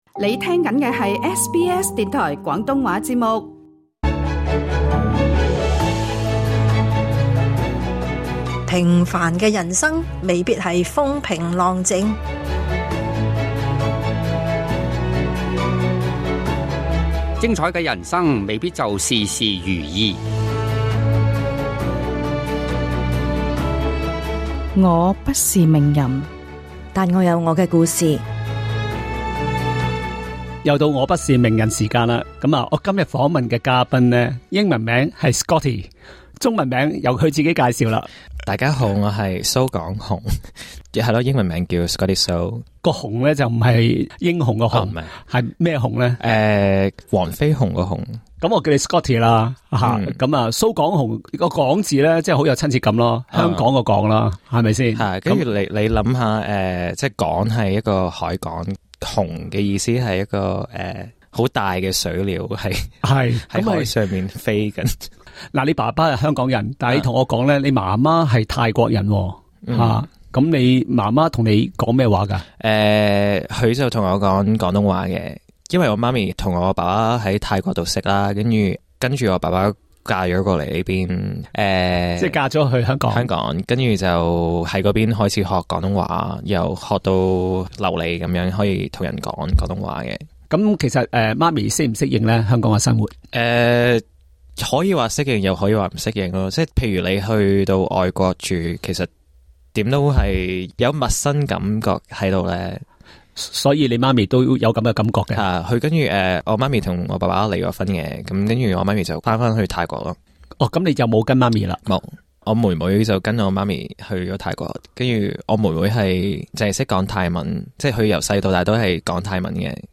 有關整個訪問，請收聽這節「我不是名人」。